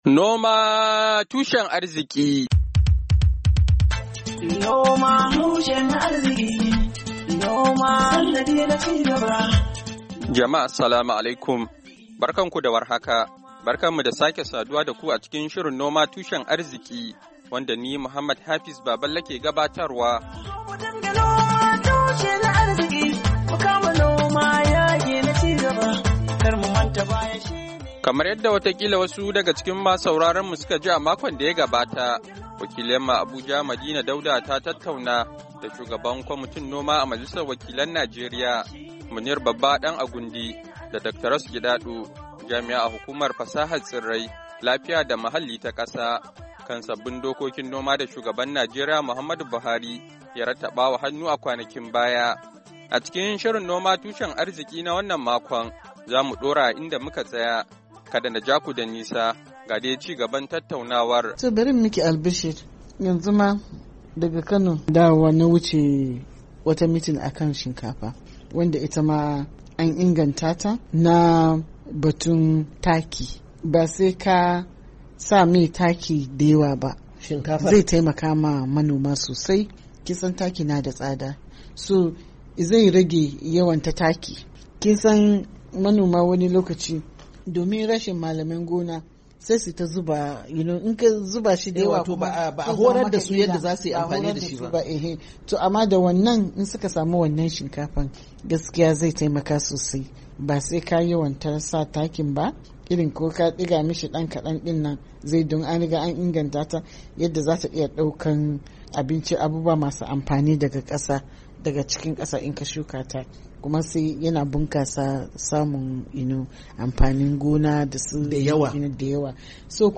A cikin shirin noma tushen arziki na wannan makon, za mu kawo mu ku ci gaban tattauna wa da kwararru kan sabbin dokokin noma da Shugaban Najeriya, Muhammadu Buhari, ya rattaba wa hannu a 'yan makonnin da su ka gabata.